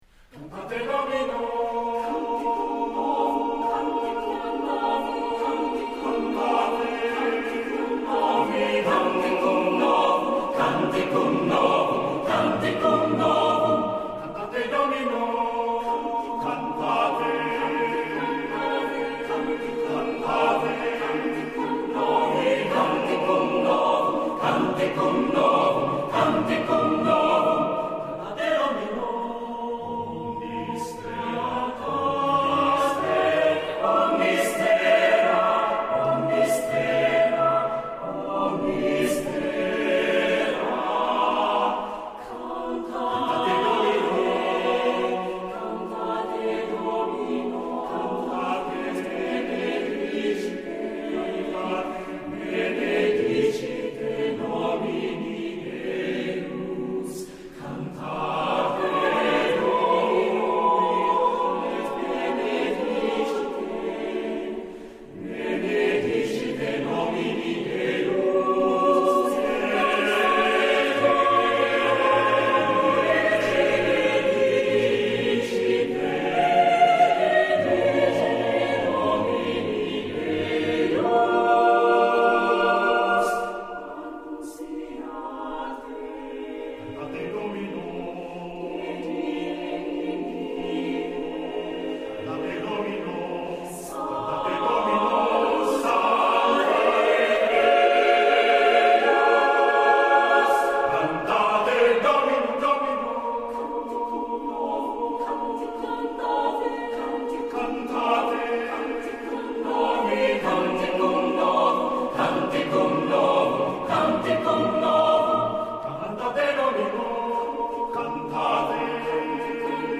Accompaniment:      A Cappella
Music Category:      Choral
Recording is SATB version.